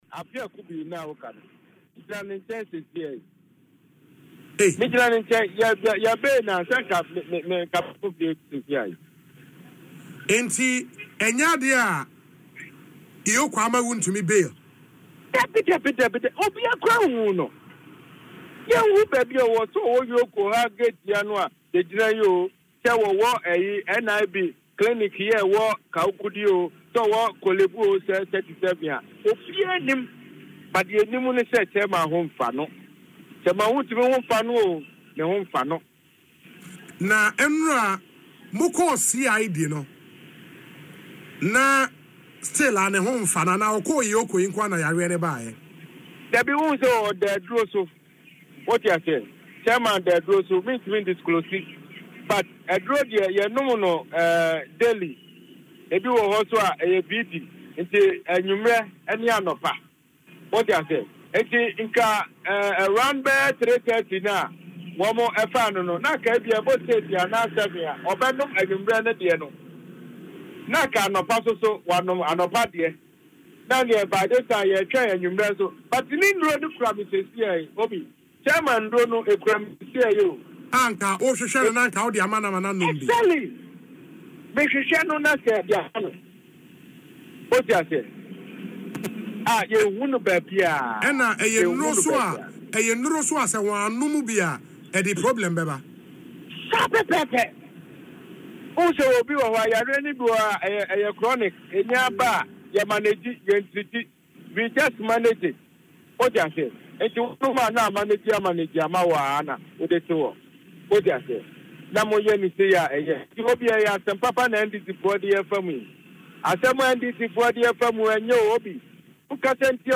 However, in an interview on Asempa FM’s Ekosii Sen